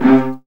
VIOLINS C3.wav